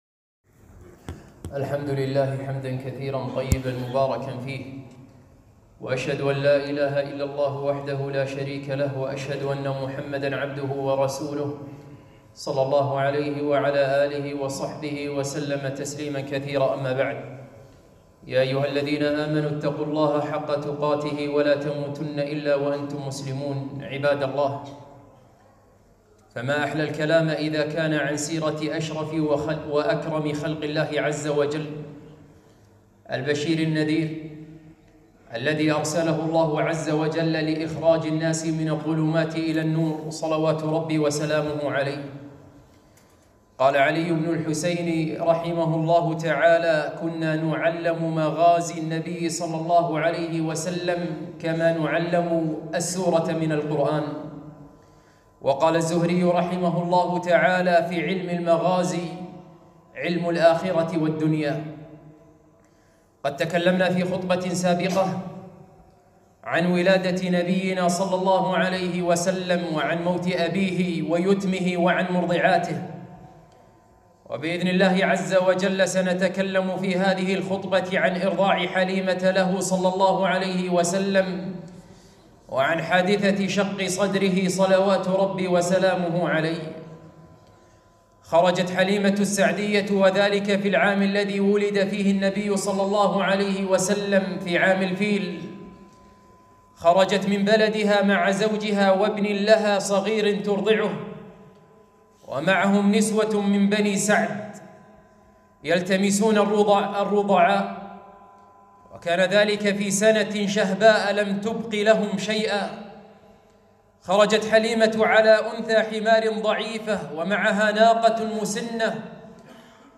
2- خطبة - قصة حليمة السعدية وحادثة شق صدر النبي